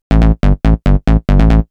Techno / Bass / SNTHBASS095_TEKNO_140_A_SC2.wav